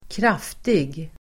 Uttal: [²kr'af:tig]